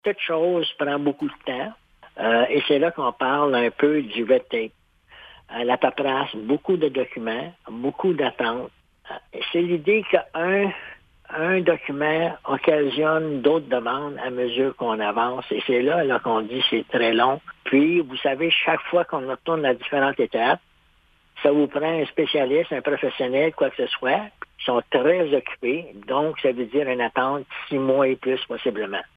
Le président trésorier de ce projet, Robert Bergeron, également maire de Kazabazua, nous explique la complexité d’un tel projet :